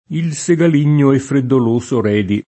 segaligno [Segal&n’n’o] agg. — più spesso che nel sign. proprio («della segale»), usato nel fig. («smilzo»): Il segaligno e freddoloso Redi [
il Segal&n’n’o e ffreddol1So r$di] (Redi) — anche segalino [